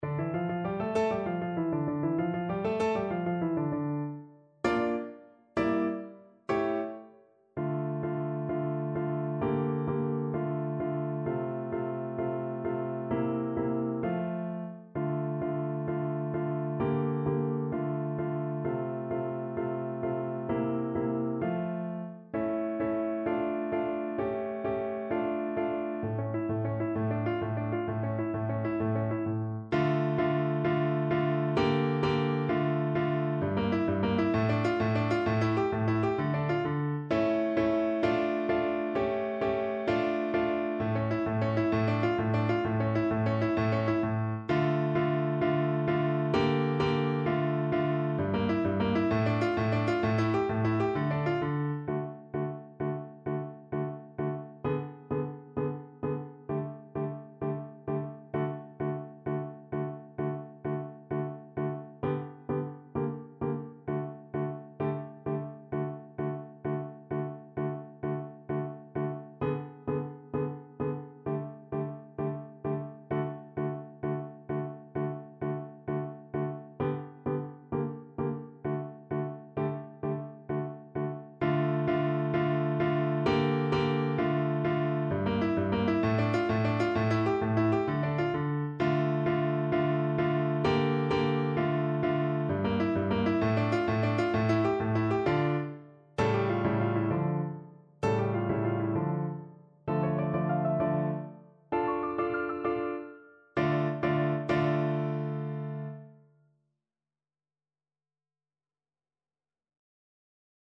Burgmüller: Tarantella (na wiolonczelę i fortepian)
Symulacja akompaniamentu